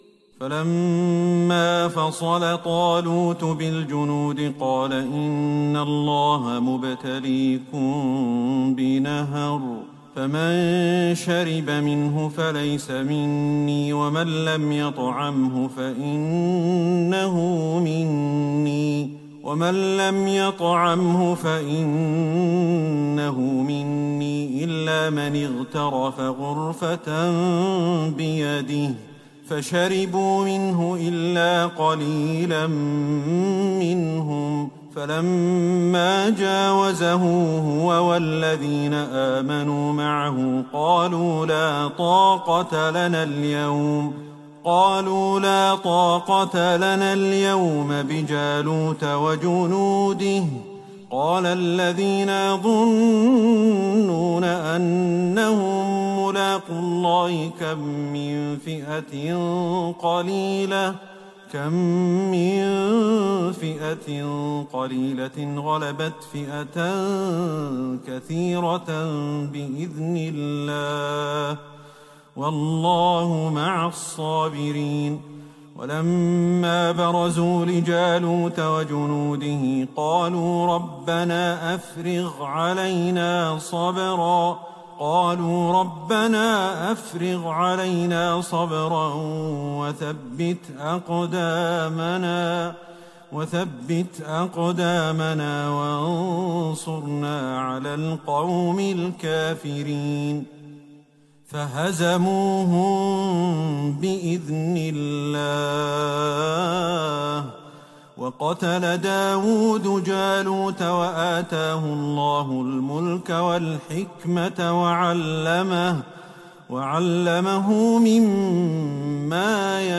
ليلة ٣ رمضان ١٤٤١هـ من سورة البقرة { ٢٤٩-٢٧٤ } > تراويح الحرم النبوي عام 1441 🕌 > التراويح - تلاوات الحرمين